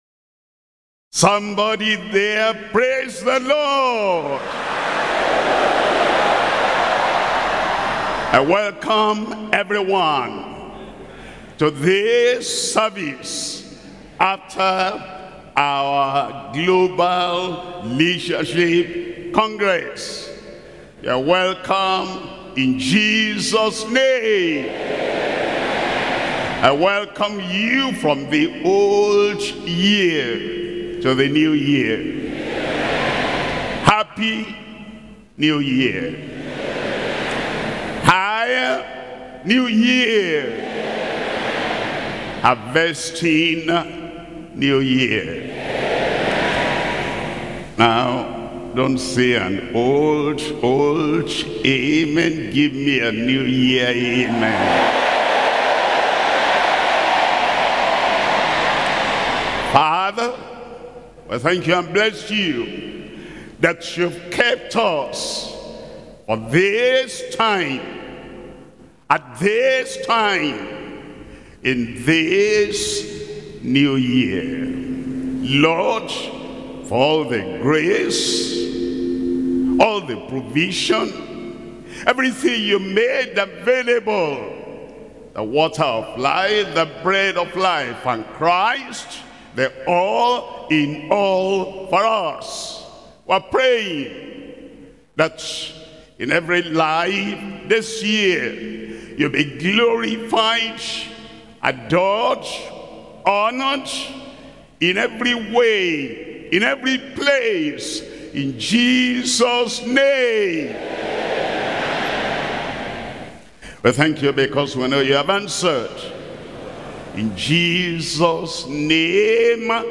Sermons - Deeper Christian Life Ministry
2026 Covenant Services